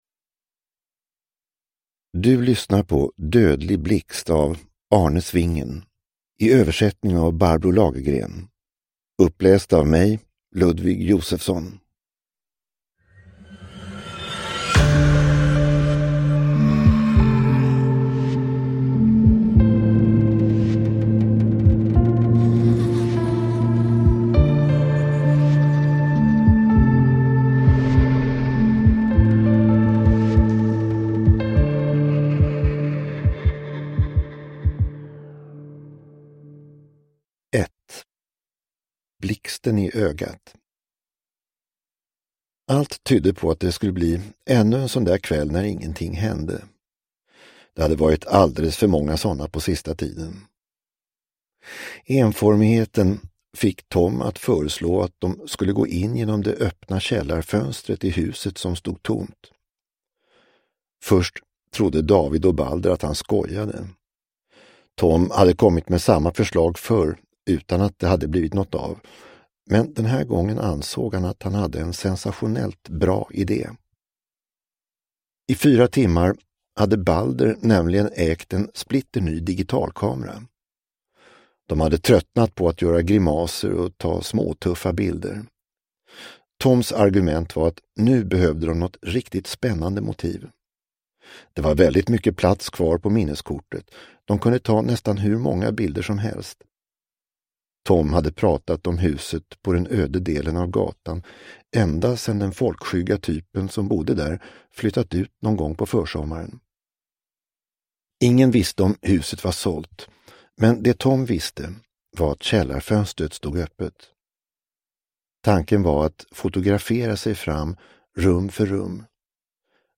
Dödlig blixt – Ljudbok – Laddas ner